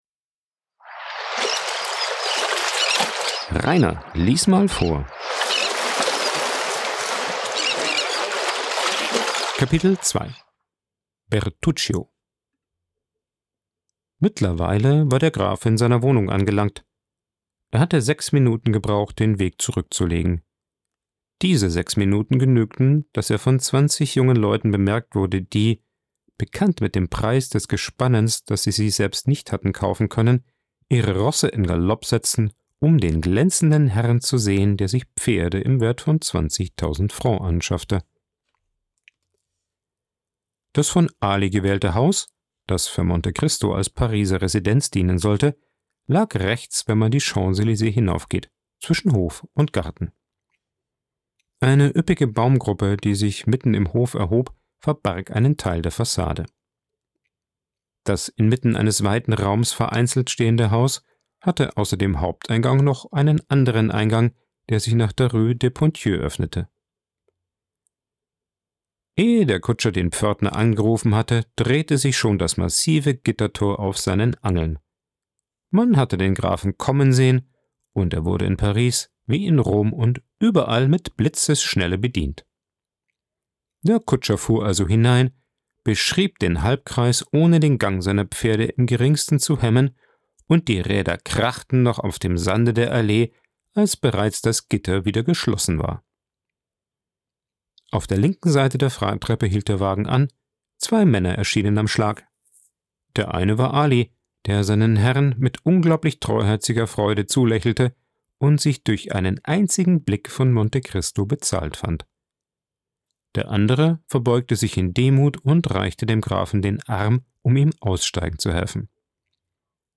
Ein Vorlese Podcast
Coworking Space Rayaworx, Santanyí, Mallorca.